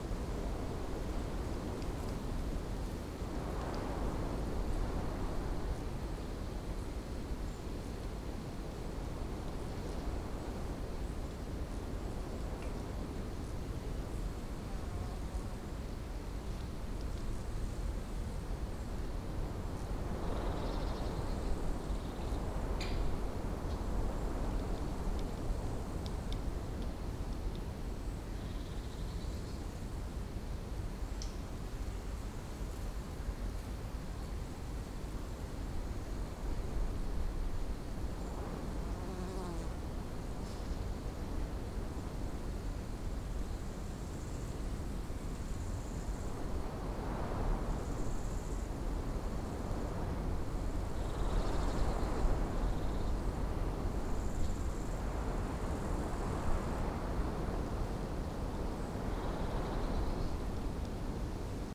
day-open-loop.ogg